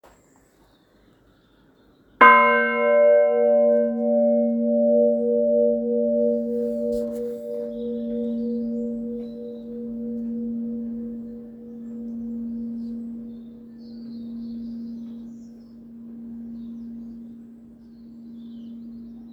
cloche n°3 - Inventaire Général du Patrimoine Culturel